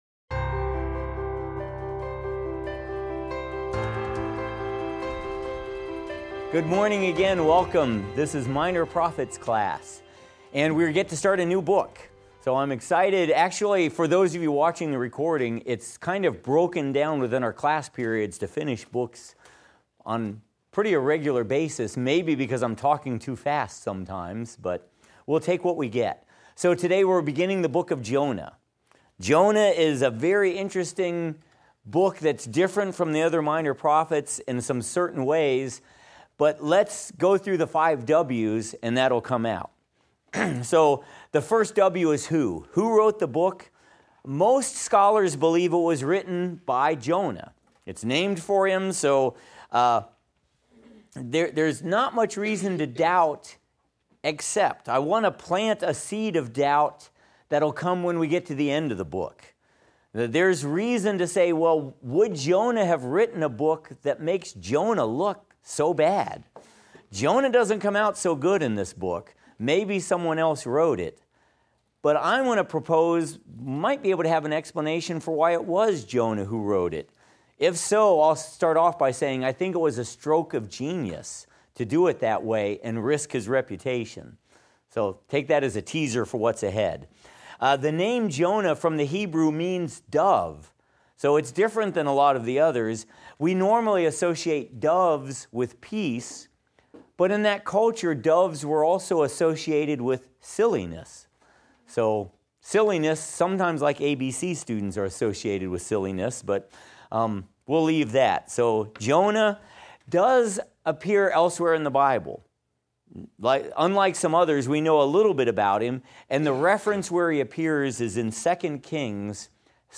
Minor Prophets - Lecture 13 - audio.mp3